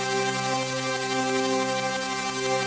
SaS_MovingPad01_90-C.wav